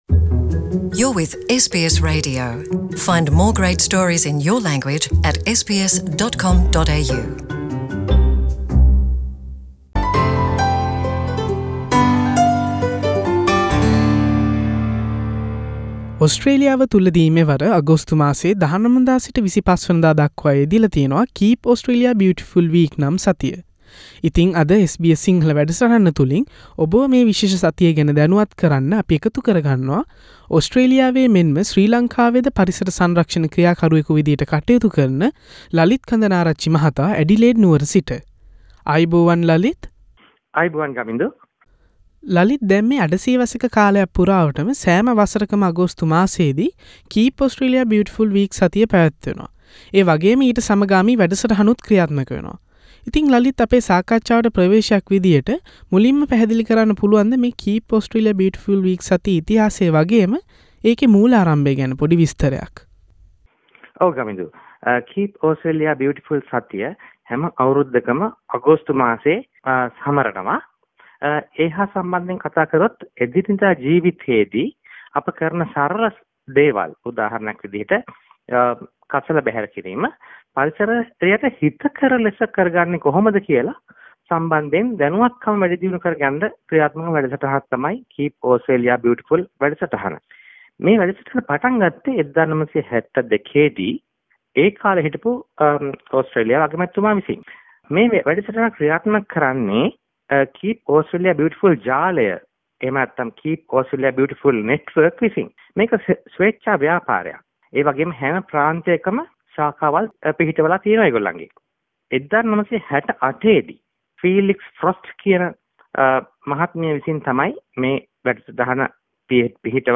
කතා බහක්